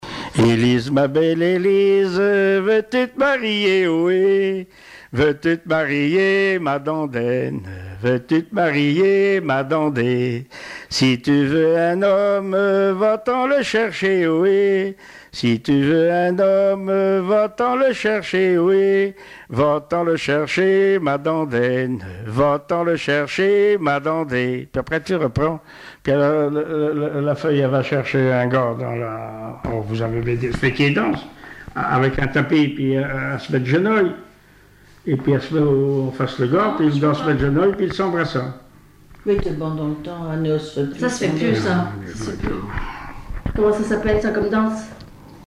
Rondes à baisers et à mariages fictifs Résumé : Dis-donc mon gros Pierre veux-tu te marier.
Témoignages et chansons traditionnelles et populaires
Pièce musicale inédite